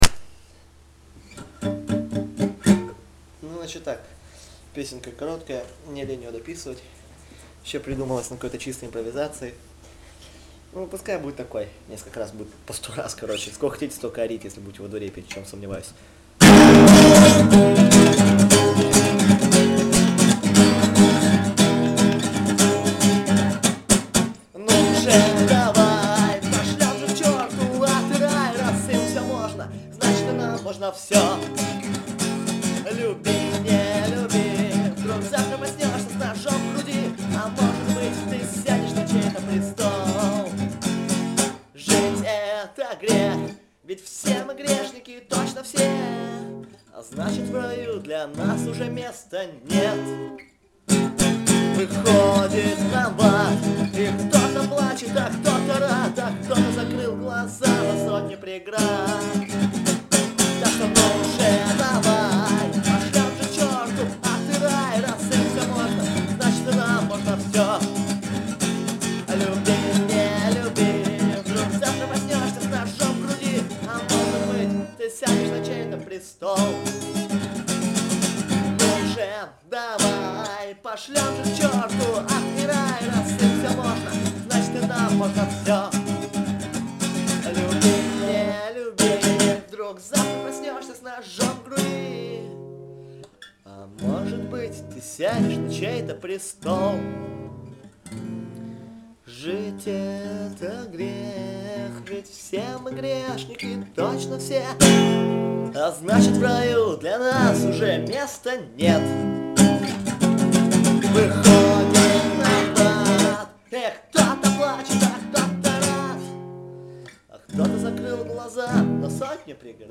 Категория: Акустика